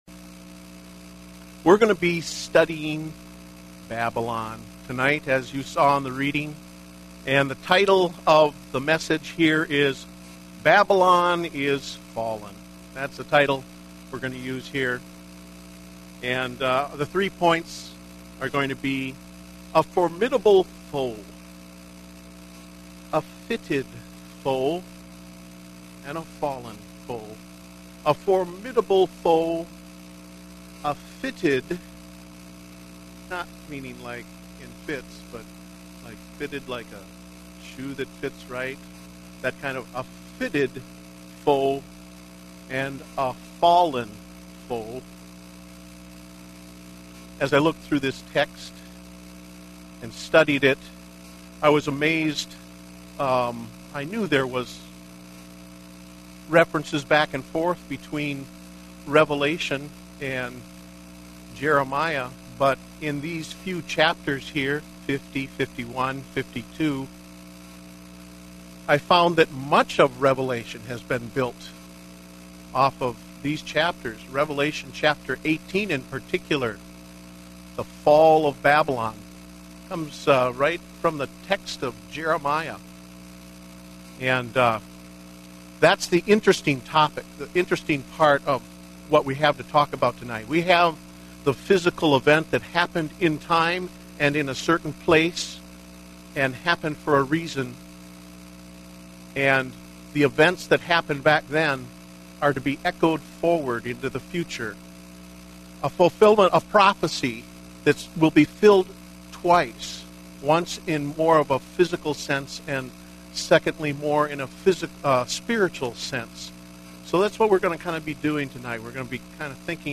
Date: November 14, 2010 (Evening Service)